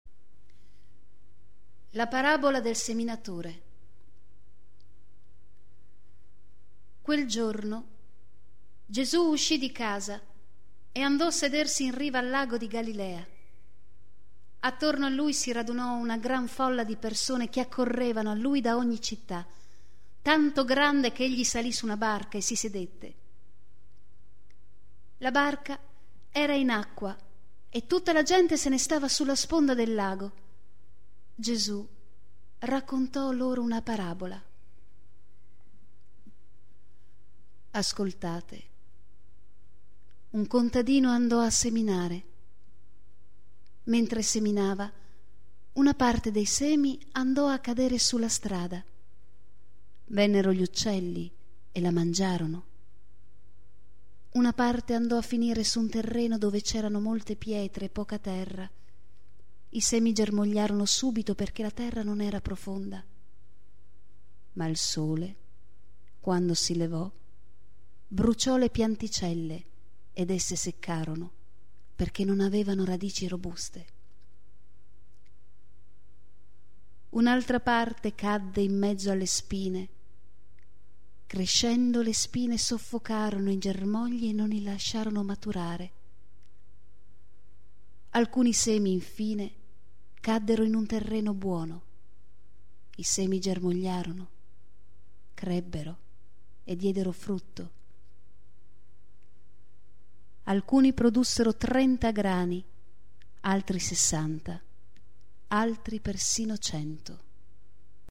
Ascolta il brano letto